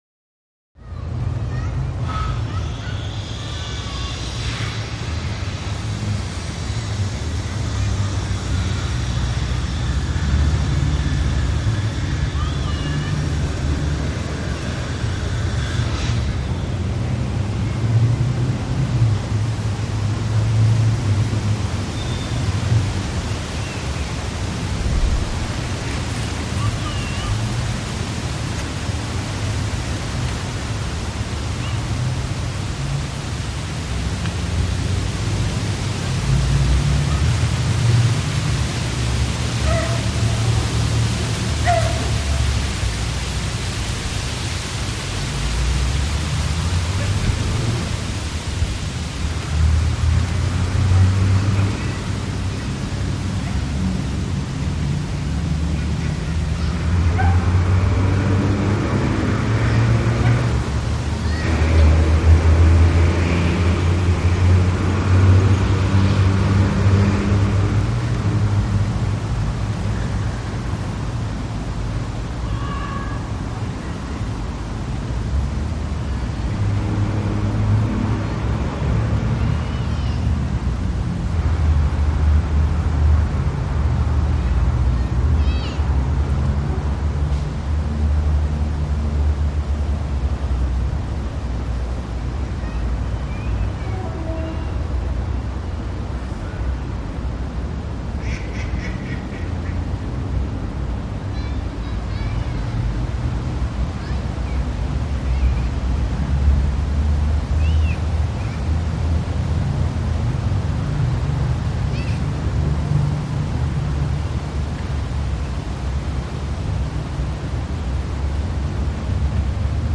Foreign Town; Brazilian Square Atmosphere. Children Playing With Electric Saw Running. Light Traffic.